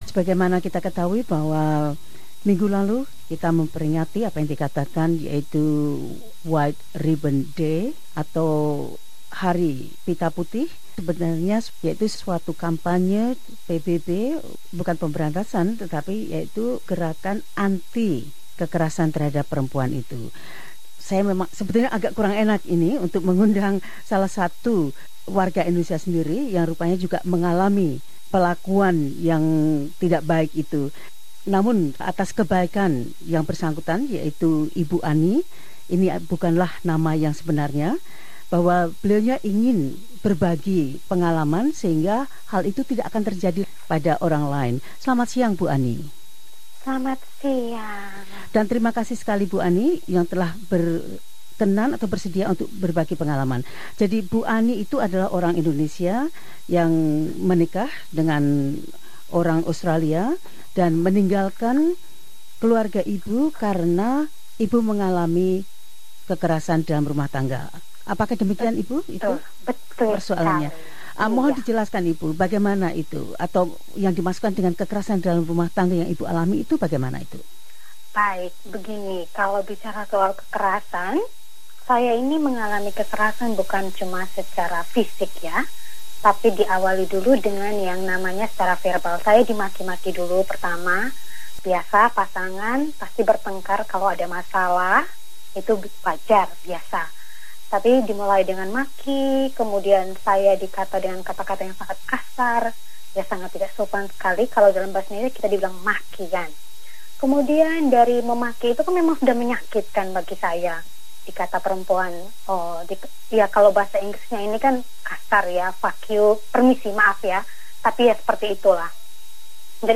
A woman affected by domestic violence Source: Press Association